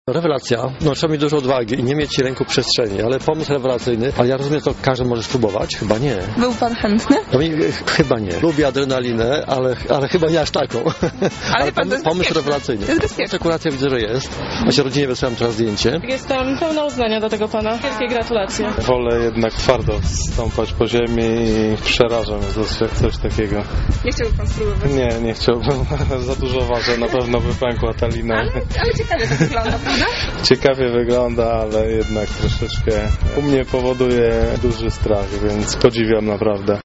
Mieszkańcy Lublina z podziwem, ale też strachem obserwowali podniebne spacery śmiałków: